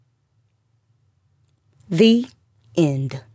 I’m not a fan of Effect > Noise Gate, but that’s how I got it to work.
Also, see in the blue waves, “End” is a lower volume than “The.” That caused problems.